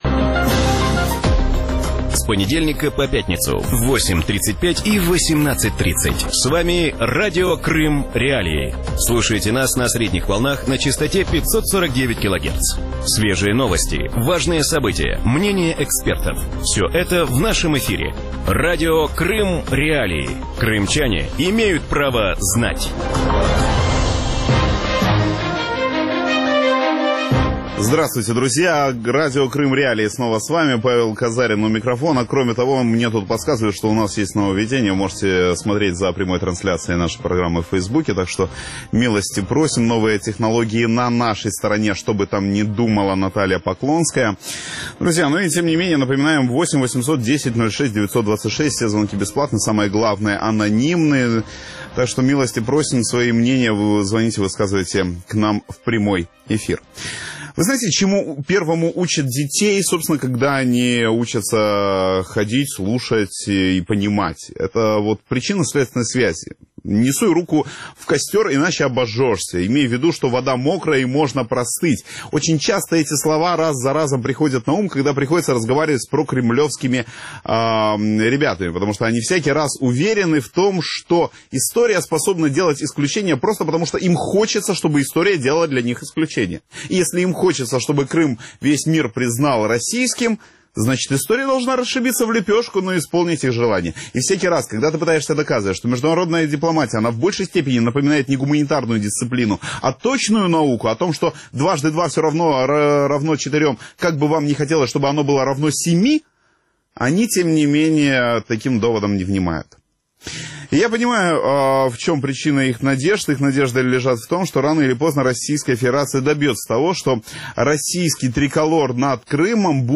Вечером в эфире Радио Крым.Реалии говорят о том как в России ищут пути для «легализации» нынешнего статуса Крыма. Каковы мотивы и какие новые идеи приходят в голову российским деятелям.